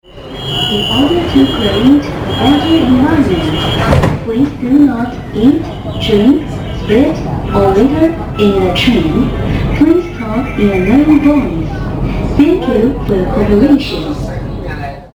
In this case, we used more than the usual modes, starting with scanning/renting bikes for a ride to the bus stop: We then caught a city bus to head south: At the south bus station, we walked to the subway: By the way, our subway has a culturally appropriate announcement, reminding the passengers (in Chinese and English) not to spit (click
Subway-Message.mp3